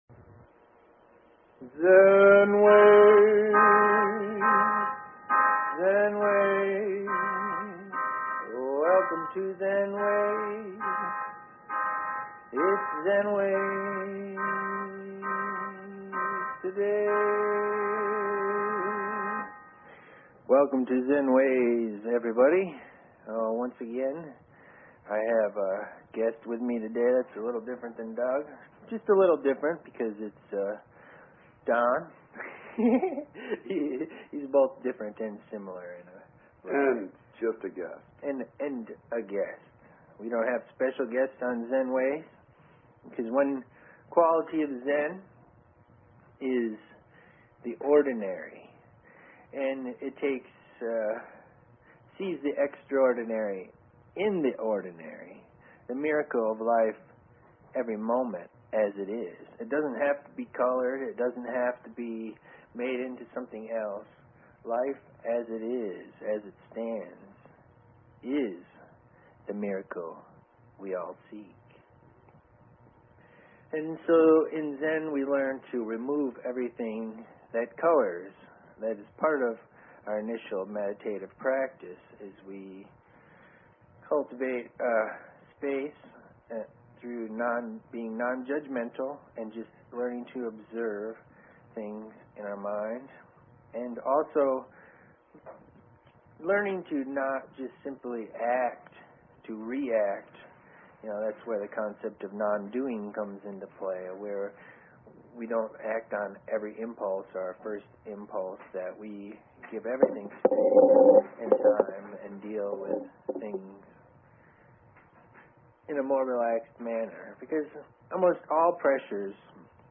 Talk Show Episode, Audio Podcast, Zen_Ways and Courtesy of BBS Radio on , show guests , about , categorized as